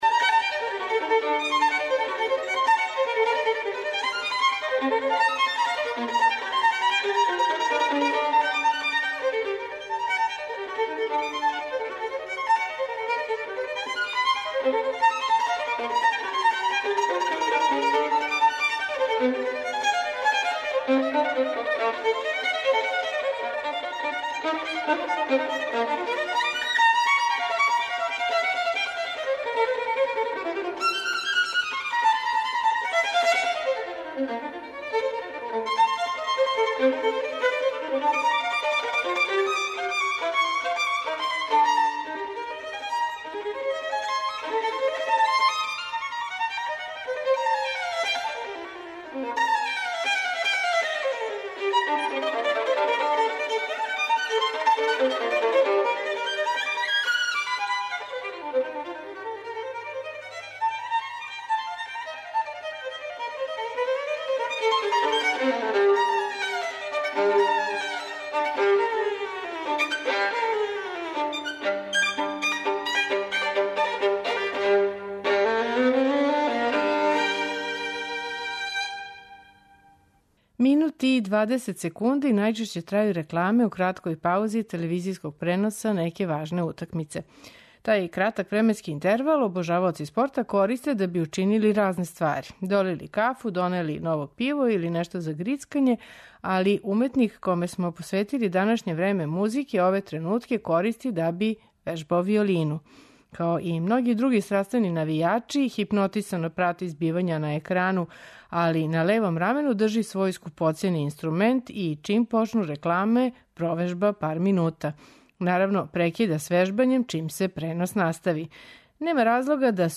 Виолиниста